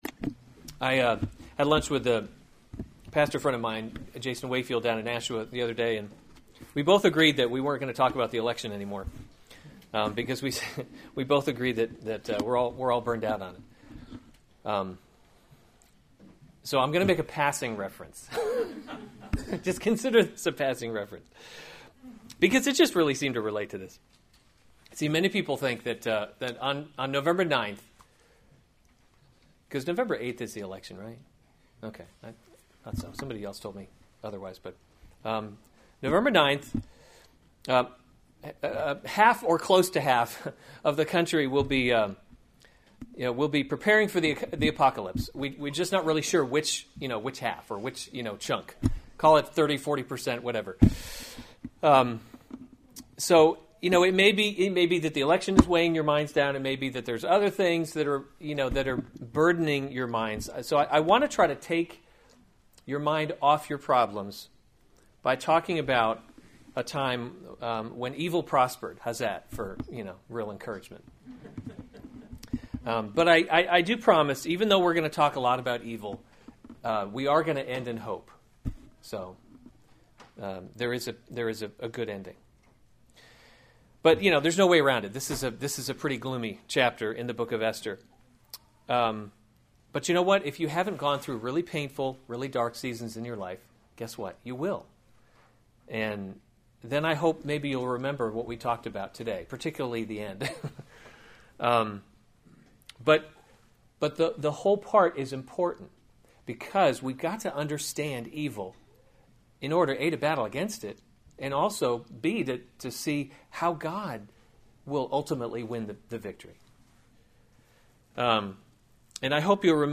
October 15, 2016 Esther: God’s Invisible Hand series Weekly Sunday Service Save/Download this sermon Esther 3:1-15 Other sermons from Esther Haman Plots Against the Jews 3:1 After these things King Ahasuerus […]